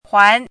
chinese-voice - 汉字语音库
huan2.mp3